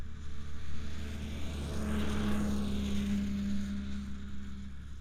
DUC Snowmobile Description Form (PDF)
DUC Subjective Noise Event Audio File - Run 1 (WAV)